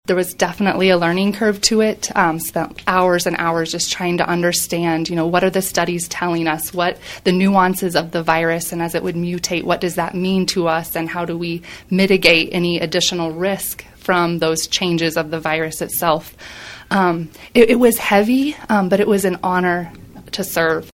Through all the changes brought about by the COVID-19 pandemic, there were two themes that underpinned Friday’s visit by Newman Regional Health administrators to KVOE’s Talk of Emporia: dedication and gratitude.